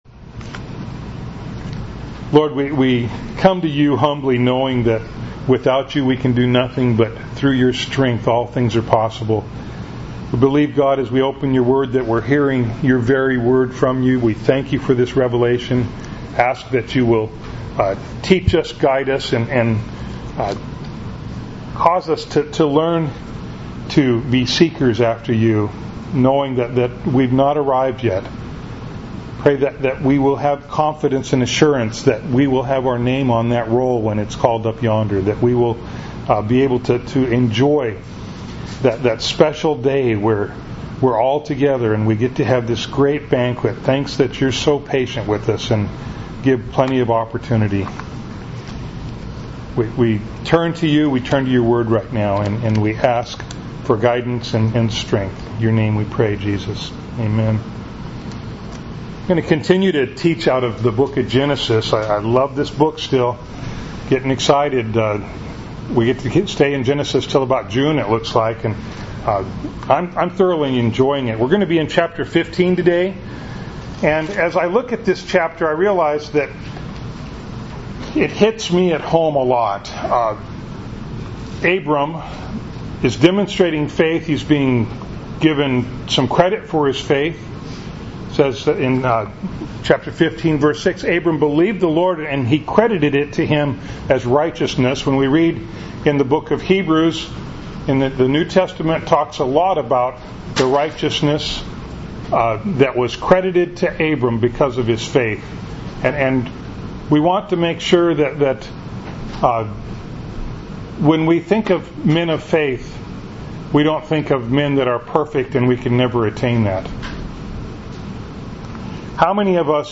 Bible Text: Genesis 15:1-21 | Preacher